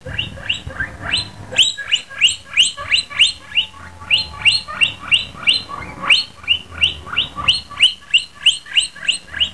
* Click to hear Baby's
queaks.